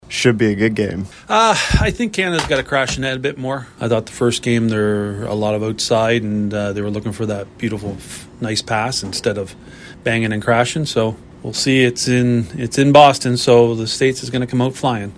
We hit the streets of Exeter-Grand Bend to get your thoughts.
can-us-streeters-2.mp3